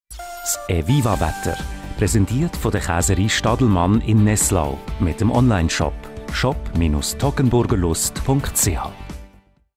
Sponsoring Wetter
Sponsoring Wetter_Eviva_Käserei Stadelmann.MP3